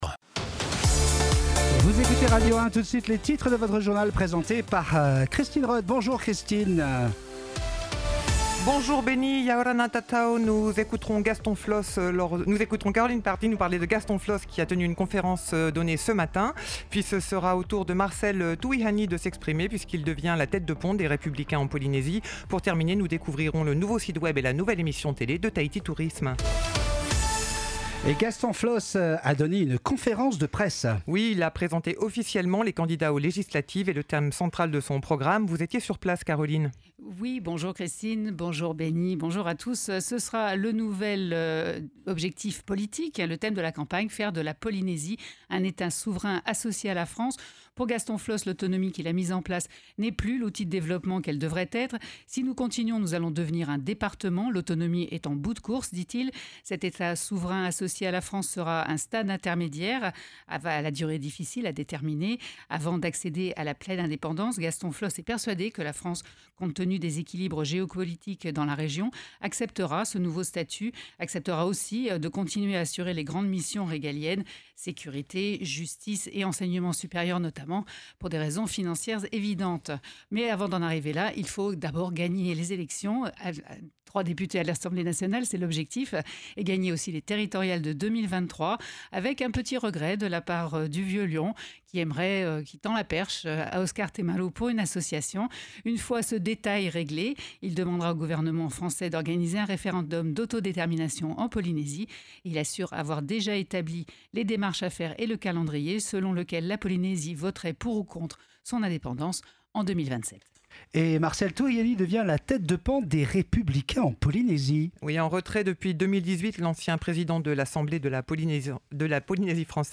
Journal de 12h, le 06/01/22